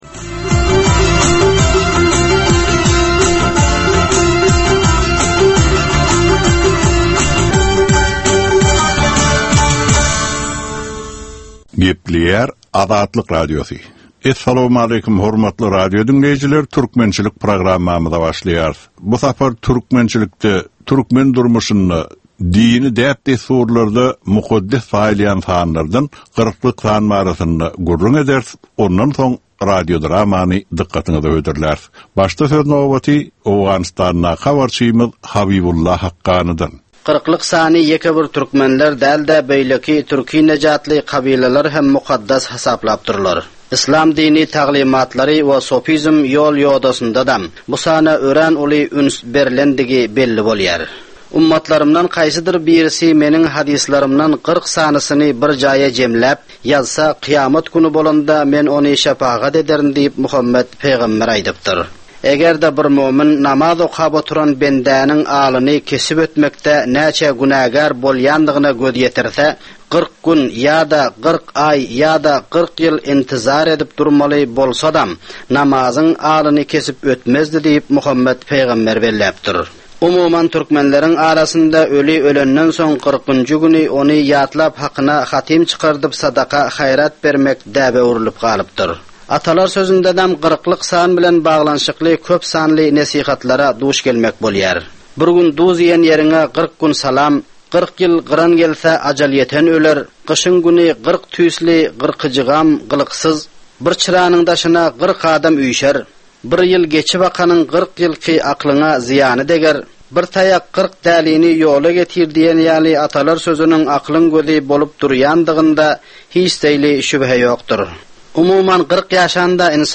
Türkmen halkynyň däp-dessurlary we olaryň dürli meseleleri barada ýörite gepleşik. Bu programmanyň dowamynda türkmen jemgyýetiniň şu günki meseleleri barada taýýarlanylan radio-dramalar hem efire berilýär.